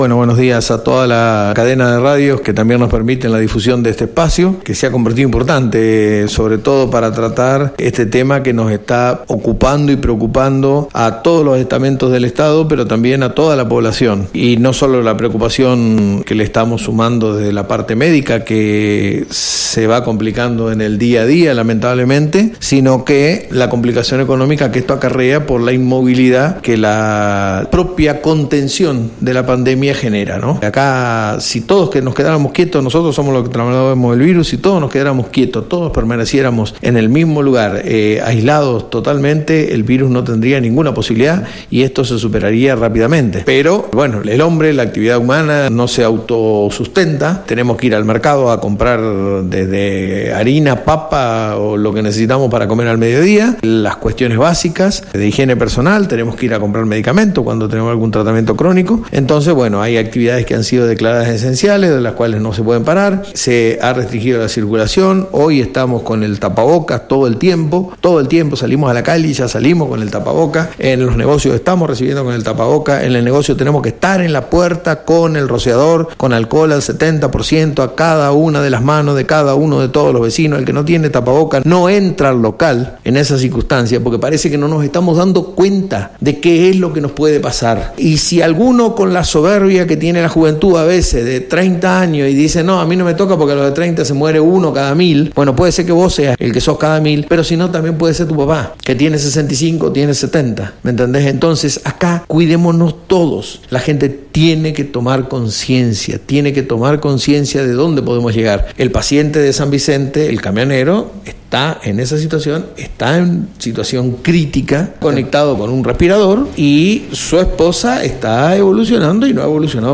El Ing. José Luis Garay jefe comunal de la localidad, dialogó con ANG respecto a las medidas que están implementando en el municipio para mantener la cuarentena.